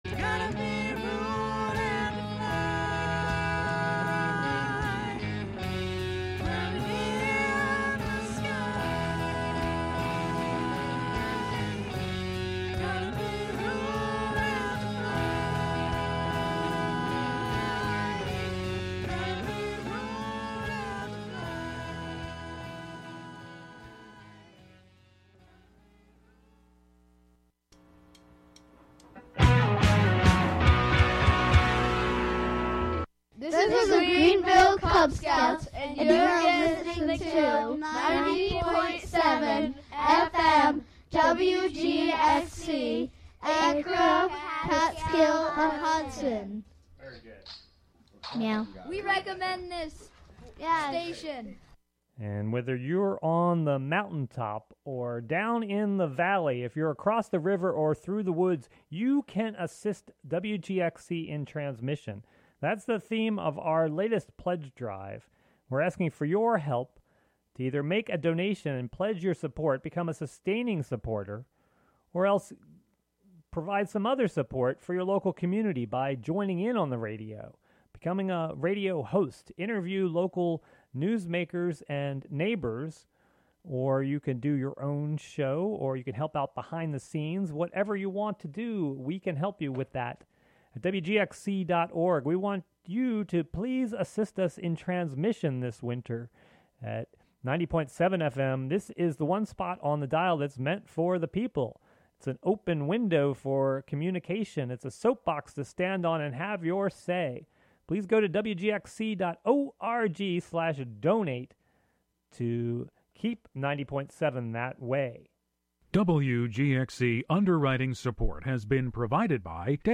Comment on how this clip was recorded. Counting down ten new sounds, stories, or songs, "American Top 40"-style.